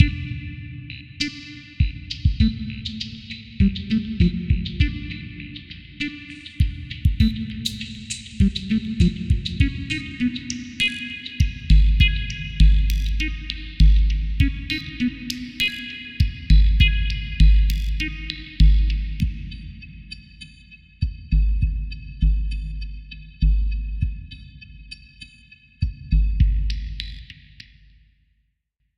Bucle de Intelligent dance music (IDM)
Música electrónica
Dance
percusión
melodía
repetitivo
rítmico
sintetizador